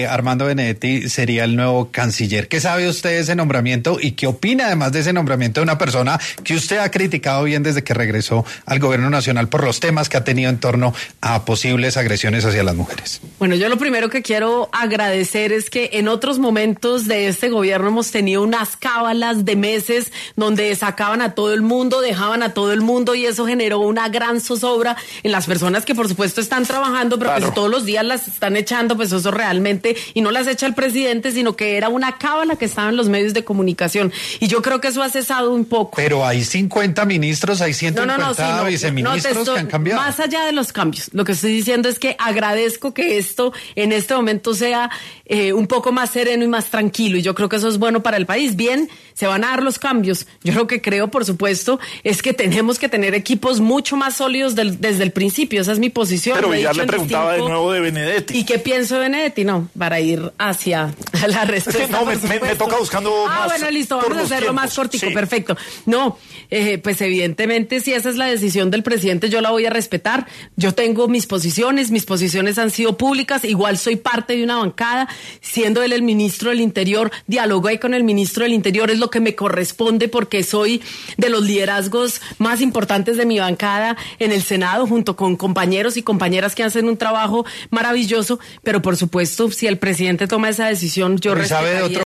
María José Pizarro estuvo en ‘Sin Anestesia’ de La Luciérnaga de Caracol Radio y habló sobre los cambios que ha traído el mandato de Gustavo Petro
En su paso por los micrófonos de La Luciérnaga de Caracol Radio, María José Pizarro, habló sobre los cambios que se han realizado durante el mandato del actual presidente Gustavo Petro.